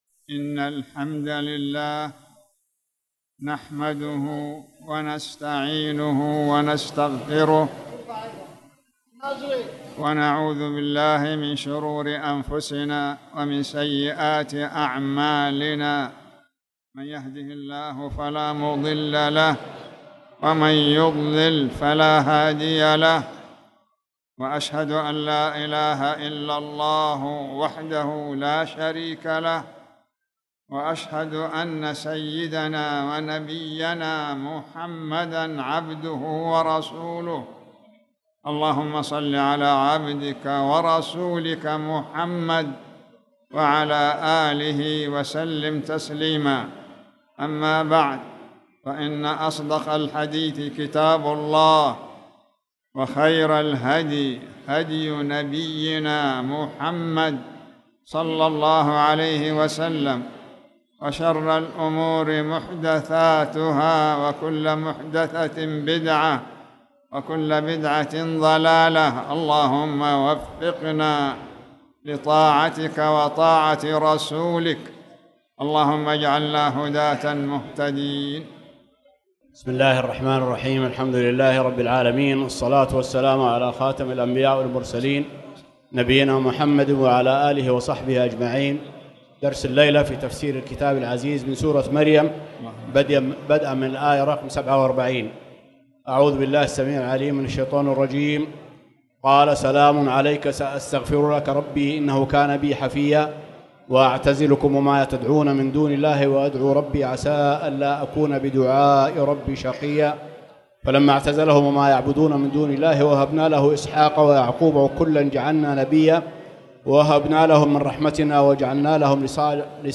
تاريخ النشر ١١ ربيع الثاني ١٤٣٨ هـ المكان: المسجد الحرام الشيخ